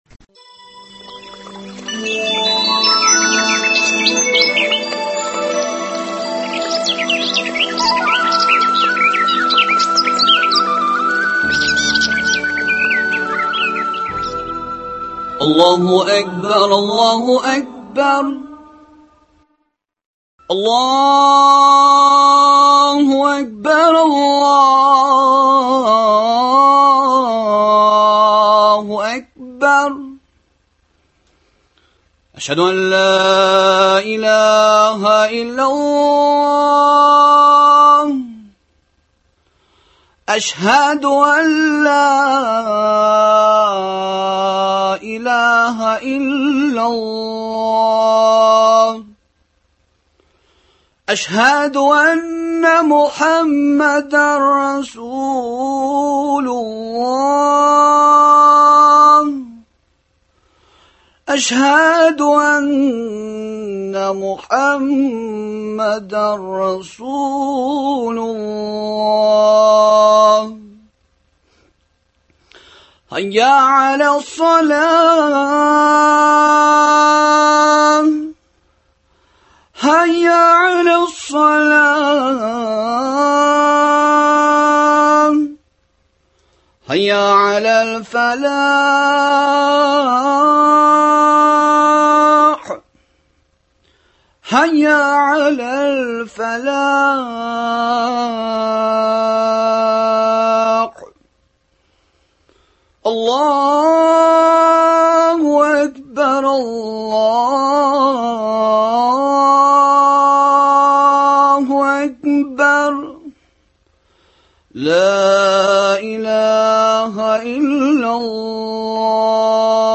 Коръәни Кәримнең Бакара сүрәсендәге 284, 285, 286 нчы аятьләренә аңлатма, аларны тормышта иркен куллану мөмкинлекләре бирә торган юллар турында сөйләшү. Дога кылуның үзенчәлекләре.